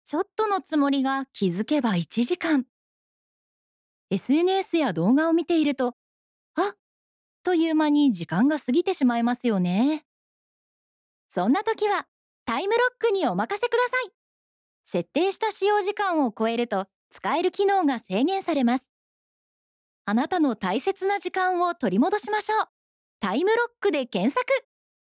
やわらかさ、透明感、温かみのある声です。
明るい、元気な
さわやか